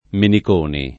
Meniconi [ menik 1 ni ]